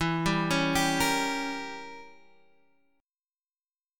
Gdim/E chord